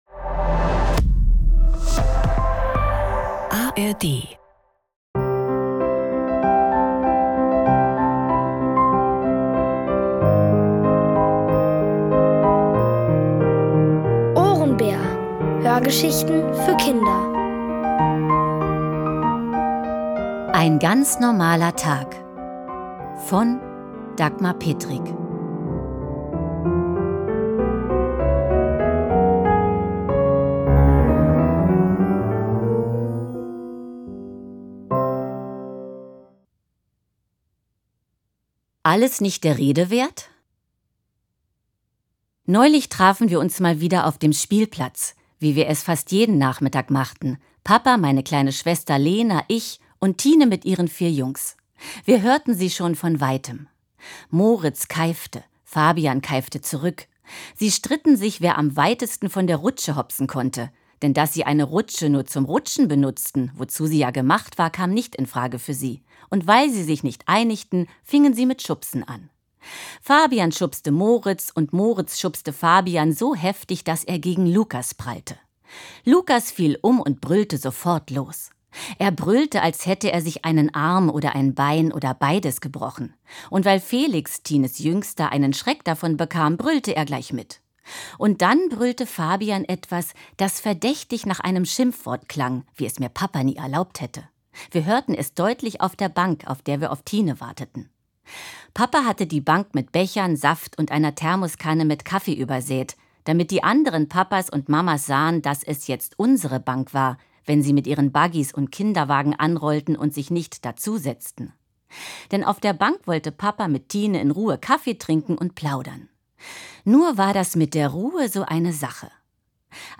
Ein ganz normaler Tag | Die komplette Hörgeschichte!